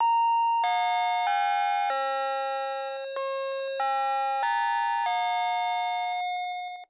Domovní zvonek  - Westminster GNU-209, 8-230V
• elektronický gong,
• zvonění: 8 tónů (melodie zvonů londýnského opatství Westminster),
• hlasitost: cca 76 dB